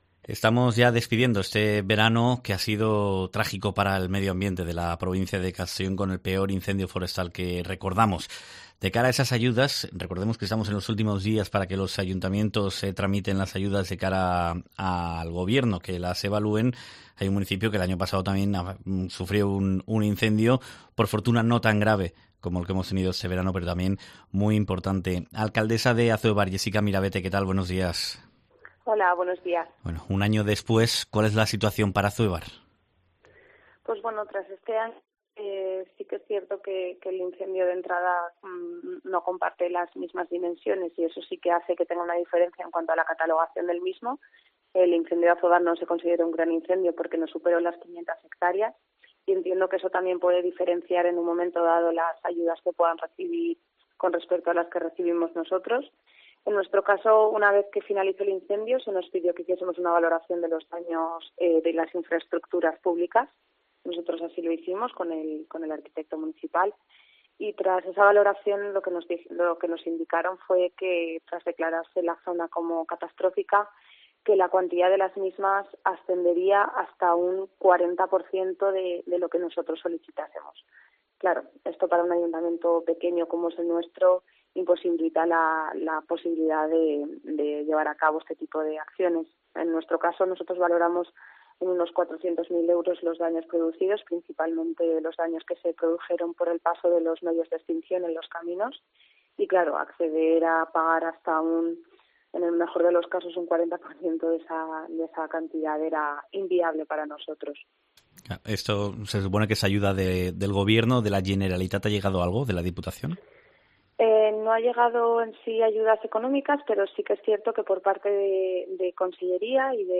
Azuébar se ha visto obligada a renunciar a las ayudas por el incendio de 2021, según explica en COPE la alcaldesa, Jéssica Miravete, al no alcanzarse las 500 hectáreas afectadas